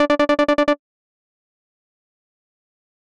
システム系ゲーム風効果音第27弾！ピピピピ…っていう連打音です！